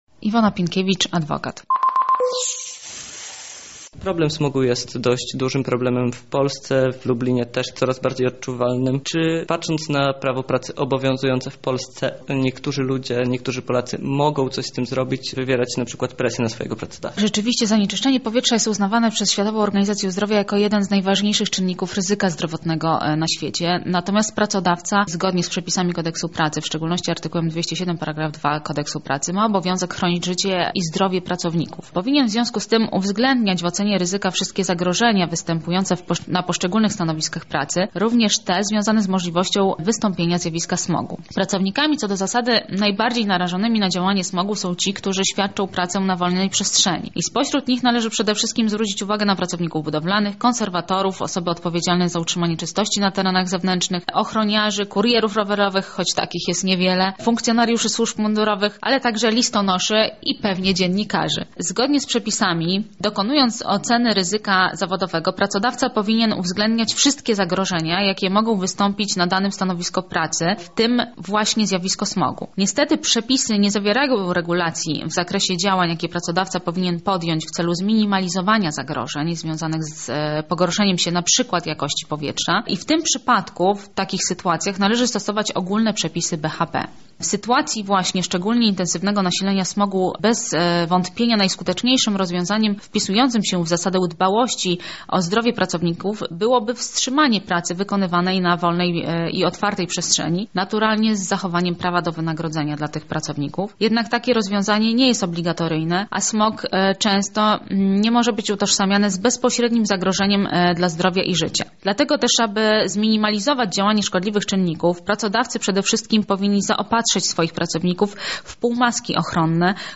W związku z tym pracodawcy powinni zapewnić odpowiednie warunki wykonywania pracy. W jaki sposób prawo pracy może chronić nas przed smogiem, a także jak zły musi być stan powietrza, aby pracodawca miał obowiązek zaopatrzyć nas chociażby w maseczki antysmogowe – o tym w rozmowie z ekspertem.